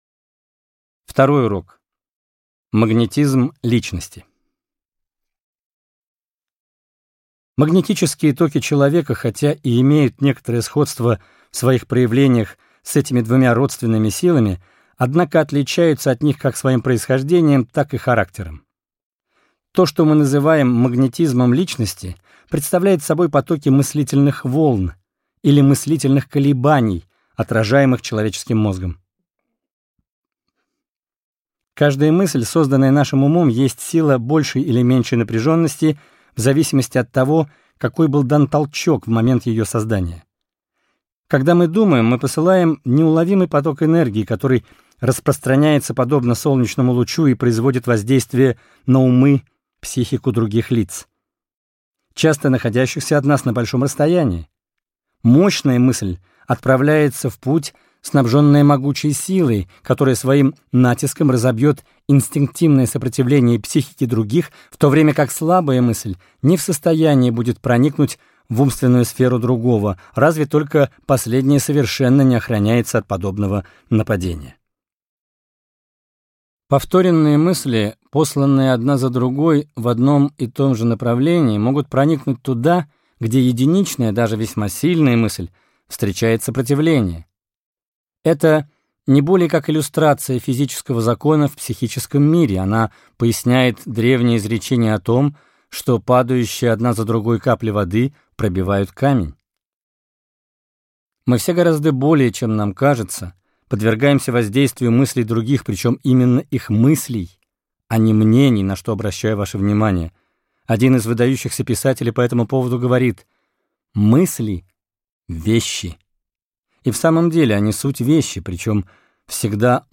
Аудиокнига Сила мысли или магнетизм личности | Библиотека аудиокниг